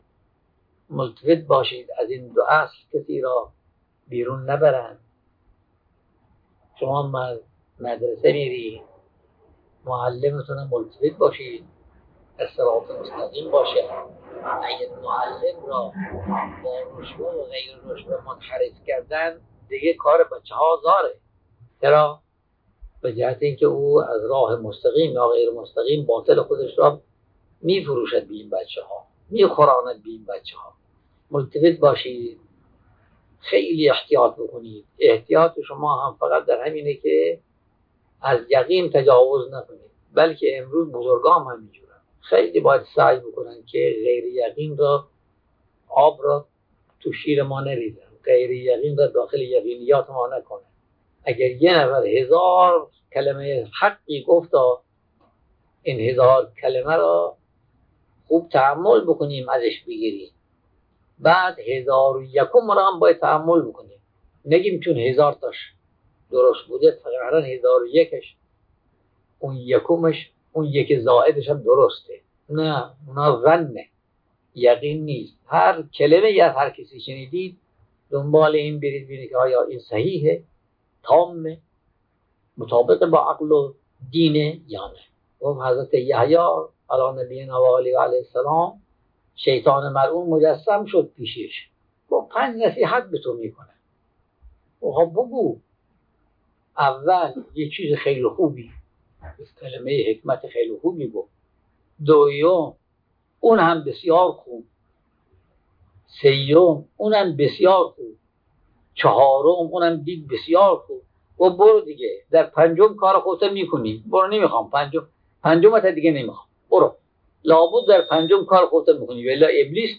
۱. شایان ذکر است، این سخنان در جلسه‌ای که جمعی از دانش‌آموزان خدمت آیت‌الله بهجت (قدس سره) رسیده بودند، ایراد گردیده است.